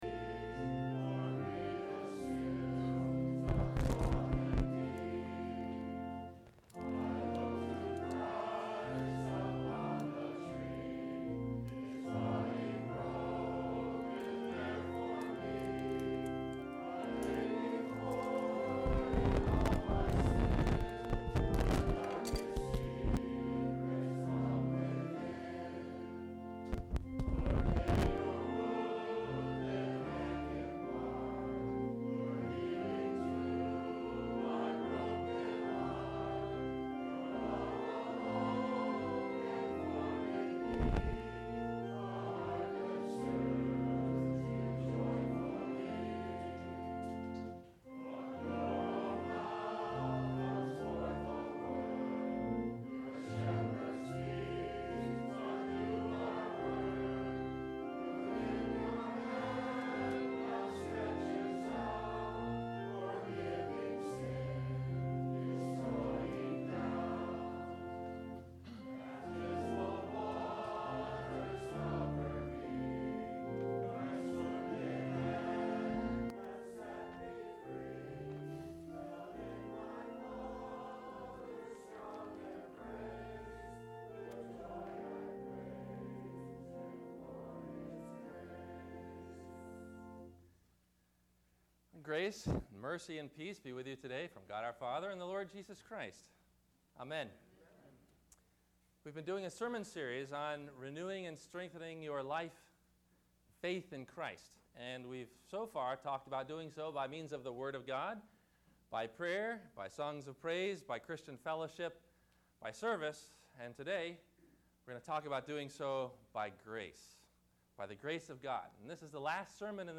Renewing Your Life By The Grace of God – Sermon – August 28 2011